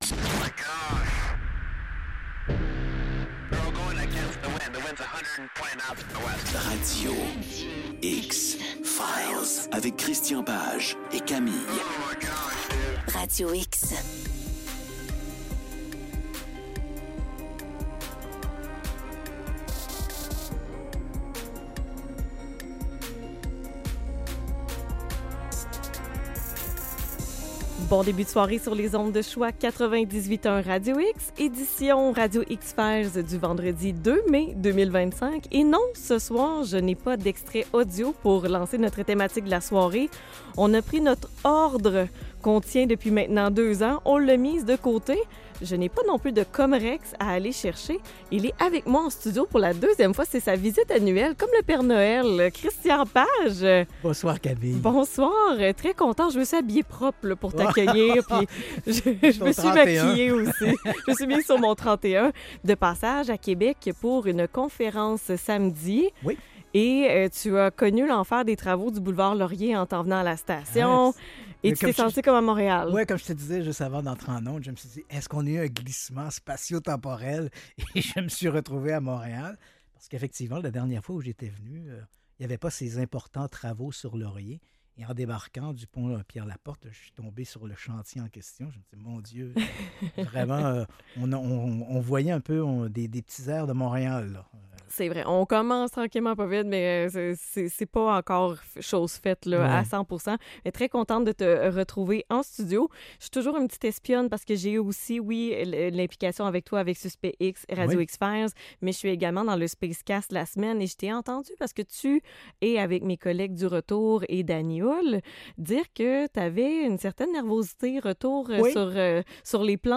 Émission spéciale
en studio. Il présente sa conférence sur les crimes occultes.